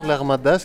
prononciation l’Armada ↘ exemple Toute une armada, on dit.
armada-mot.mp3